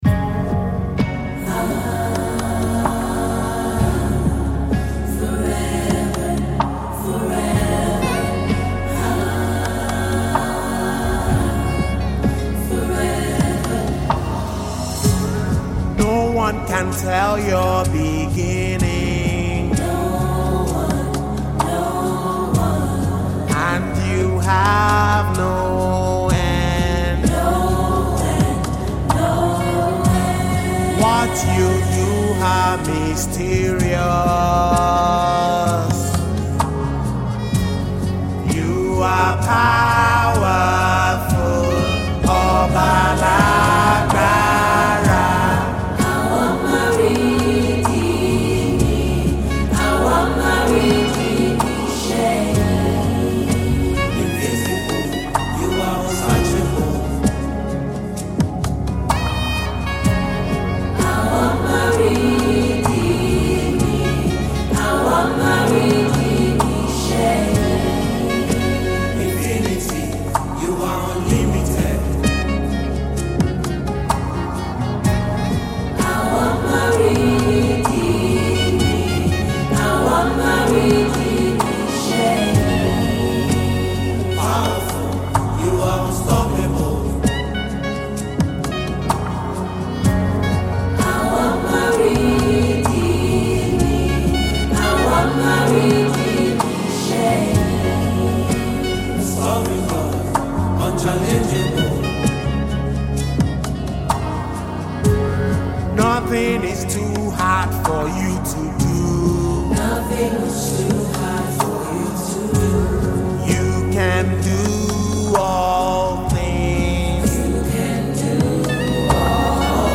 Music
worship melody